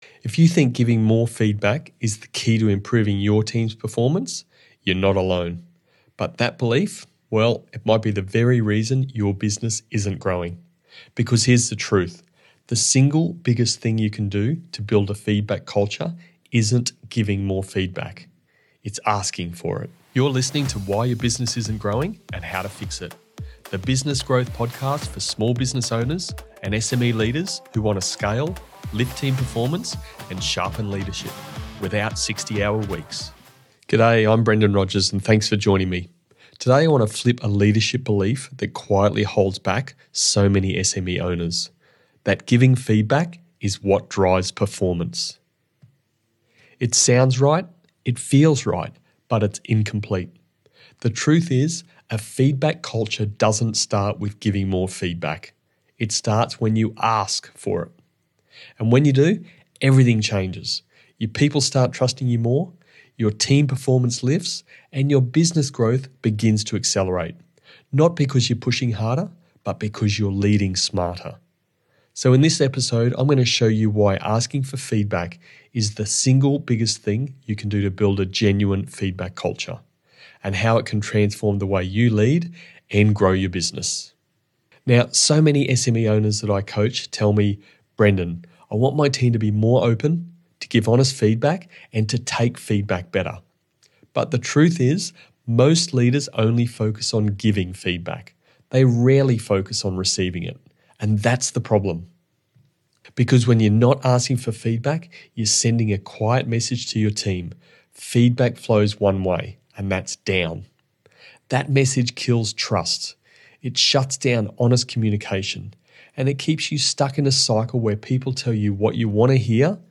If you want to lift team performance, strengthen trust, and create a culture where people thrive, this short solo episode will help you lead the way.